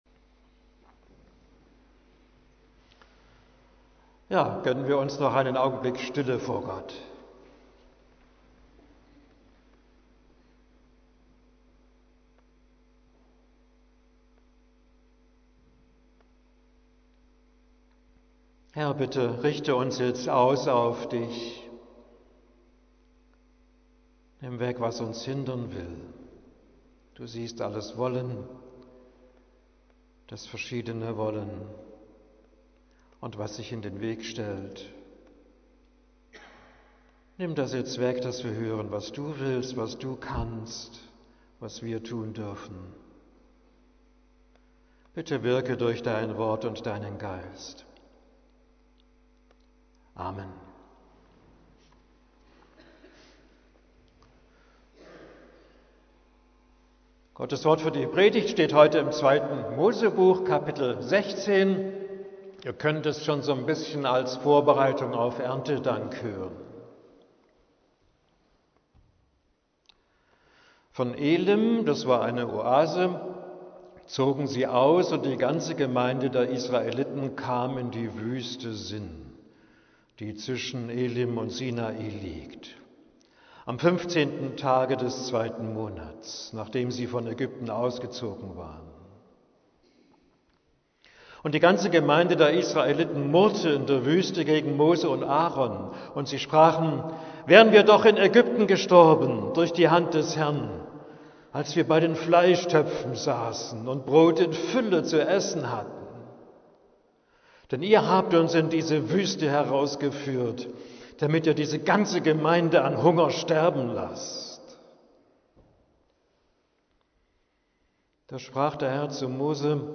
(Predigt für den 7. Sonntag nach Trinitatis)